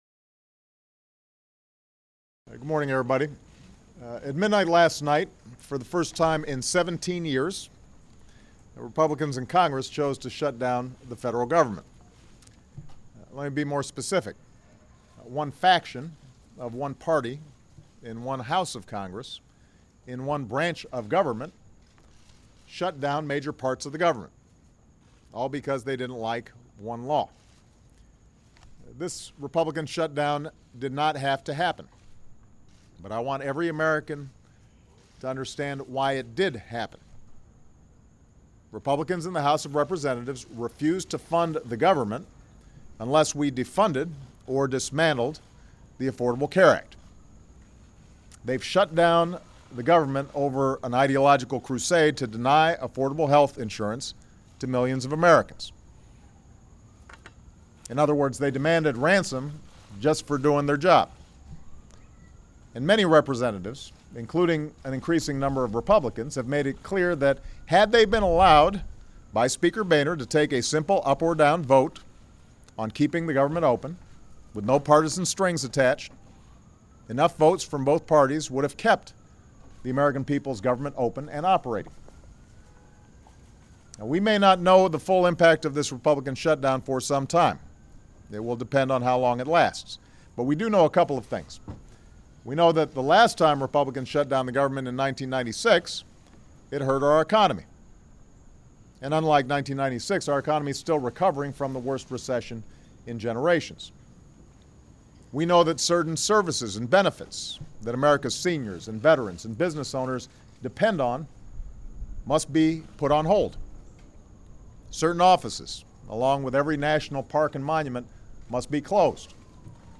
U.S. President Barack Obama delivers a statement to the press on the Affordable Care Act (ACA) and the government shutdown